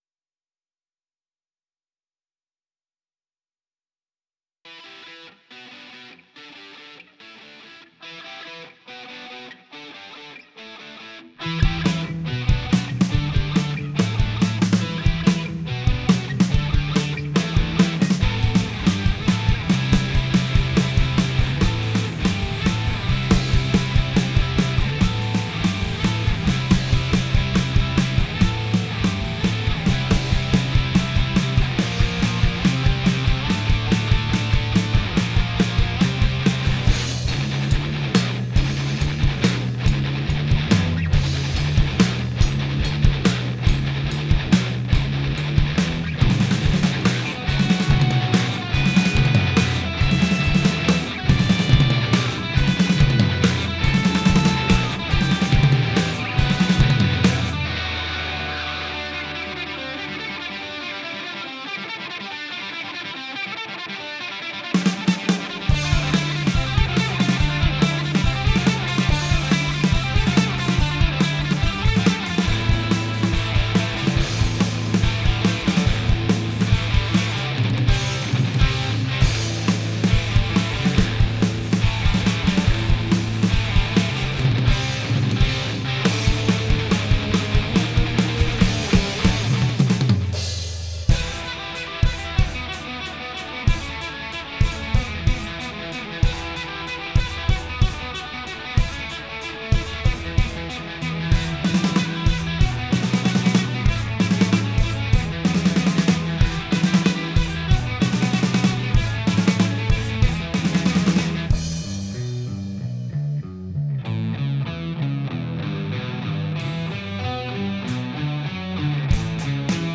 Instrumental Guitar Rock (2018)
It really brings out the players on their instrumentation.